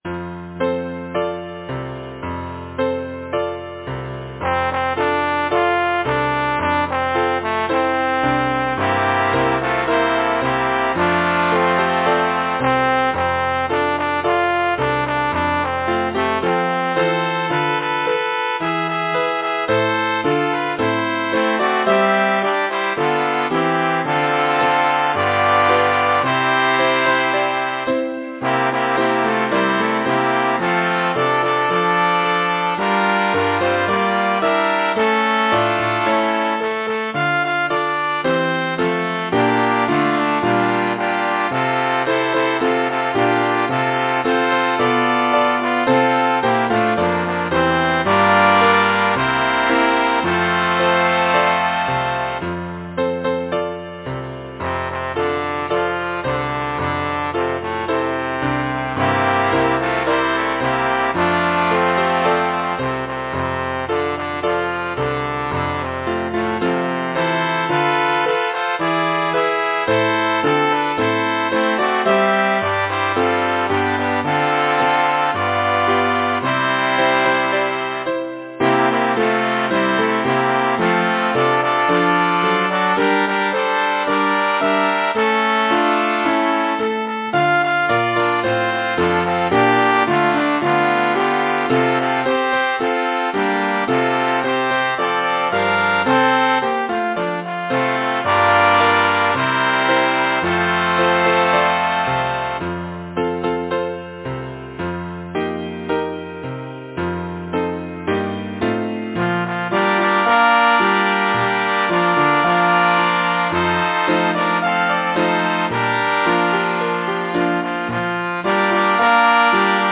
Title: Little Lac Grenier Composer: George Whitefield Chadwick Lyricist: William Henry Drummond Number of voices: 4vv Voicing: SATB Genre: Secular, Partsong
Language: English Instruments: Piano